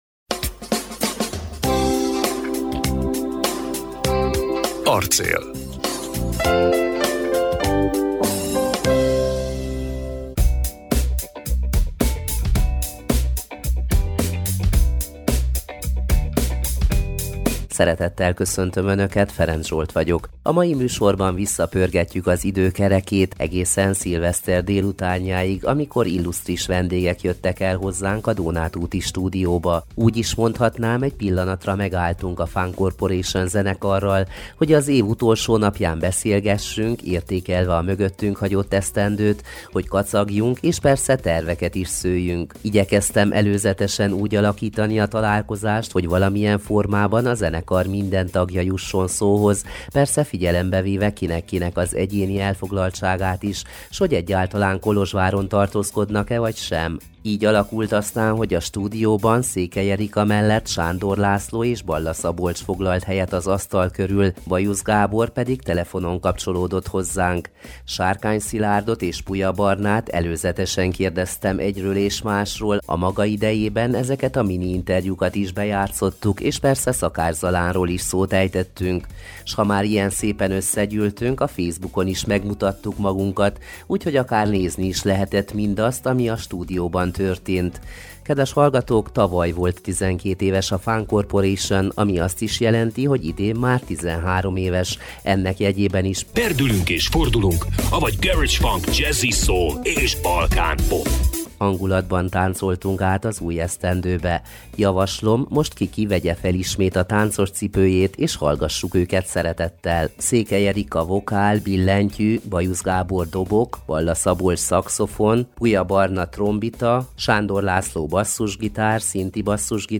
Szilveszterkor egy pillanatra megálltunk a FUNKorporation zenekarral, hogy beszélgessünk, értékelve a mögöttünk hagyott esztendőt, hogy kacagjunk, és persze terveket is szőjünk.